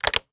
taxi_hangup.wav